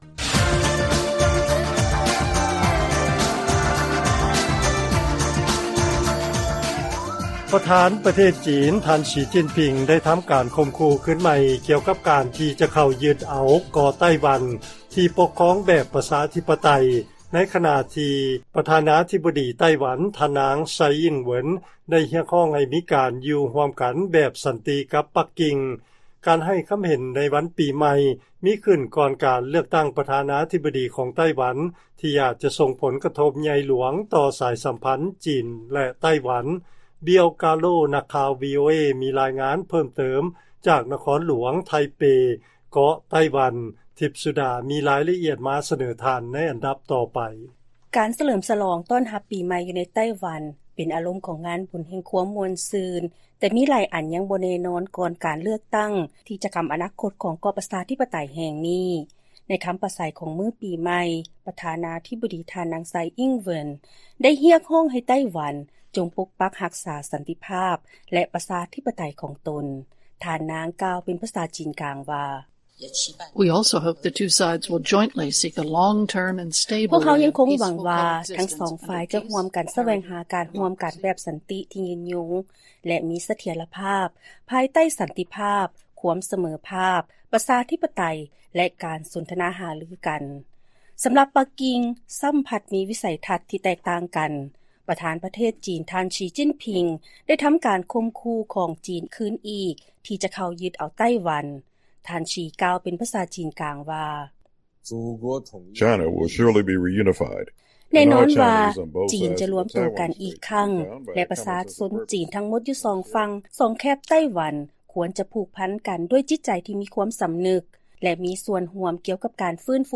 Ringing in the new year in Taiwan. A festive mood.
Tsai Ing-wen, Taiwan President)) ((In Mandarin, female.
Xi Jinping, China President, In Mandarin, male.